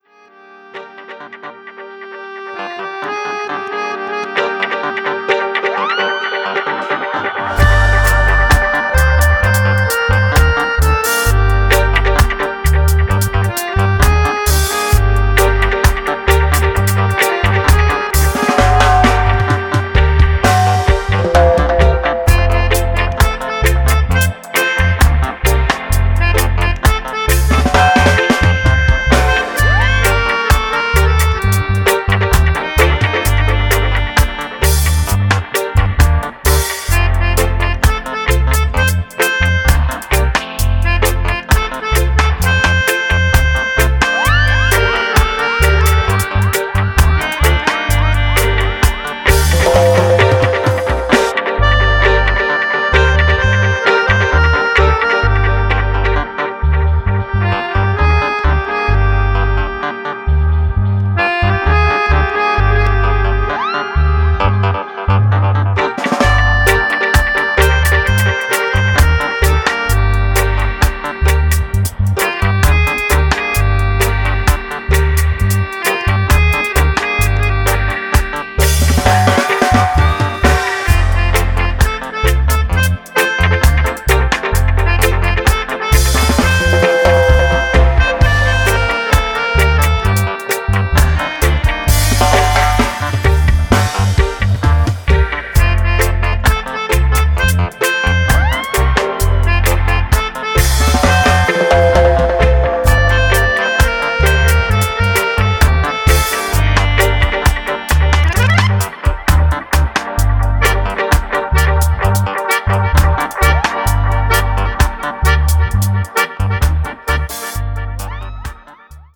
Reggae/Dub